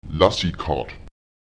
Lautsprecher lasekát [Èlasekaùt] der Stift (das Schreibgerät)